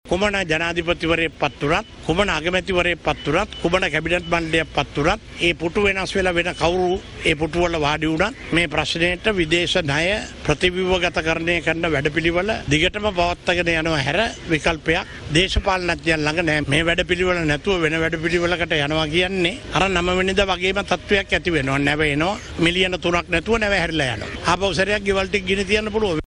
ඒ මහතා මෙම අදහස් දැක්වීම සිදු කළේ කොළොඹ පැවති මාධ්‍ය හමුවකට එක්වෙමින් .